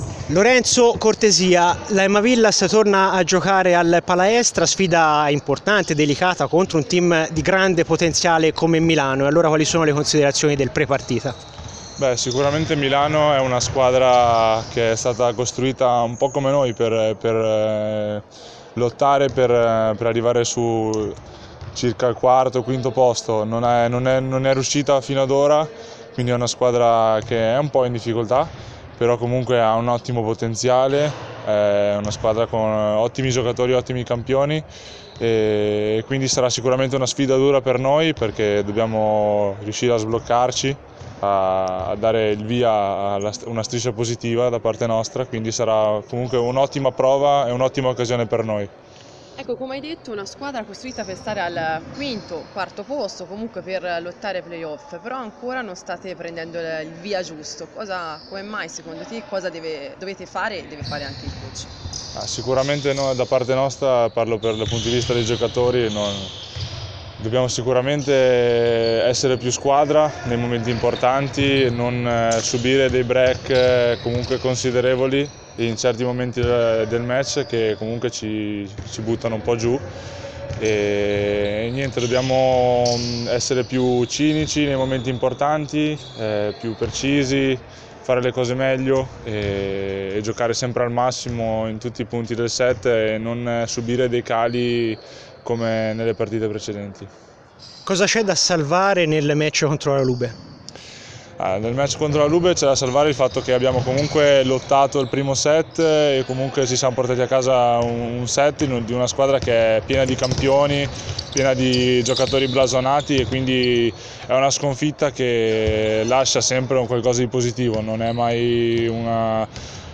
Conferenza stampa in casa Emma Villas Siena.